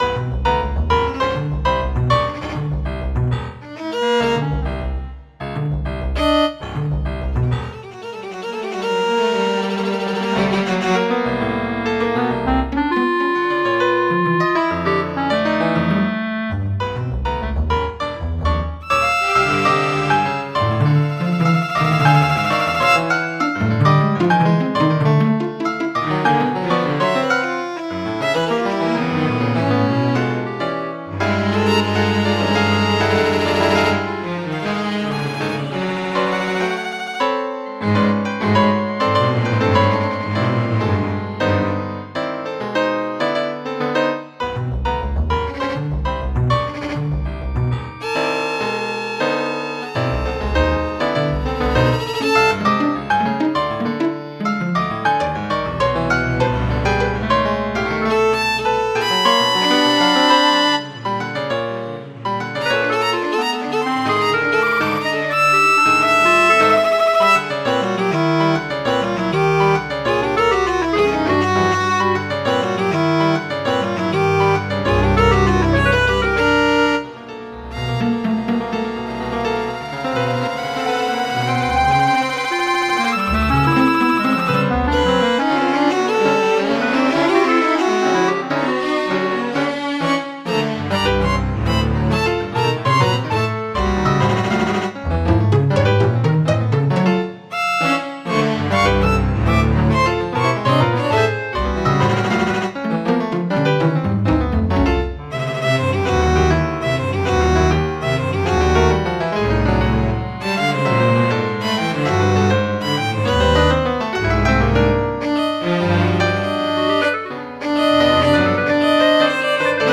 Música de Cámara
Para violín, clarinete, piano y violonchelo.
1er movimiento. (Versión MIDI)